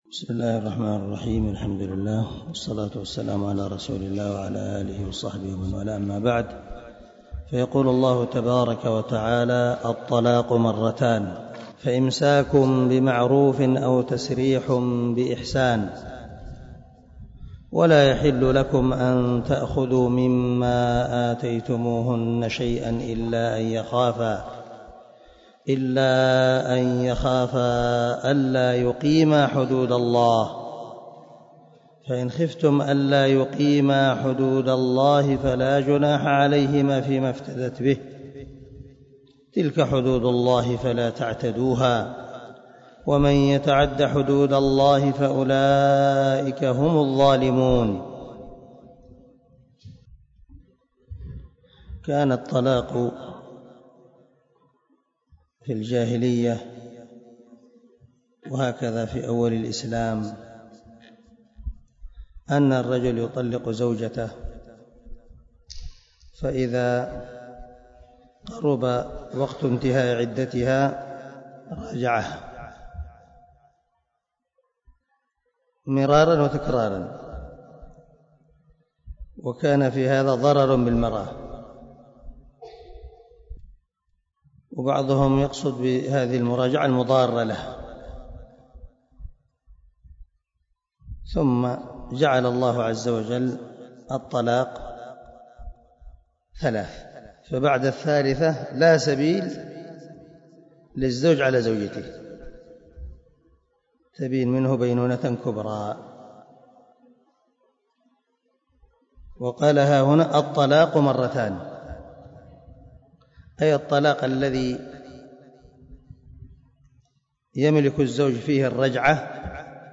116الدرس 106 تفسير آية ( 229 ) من سورة البقرة من تفسير القران الكريم مع قراءة لتفسير السعدي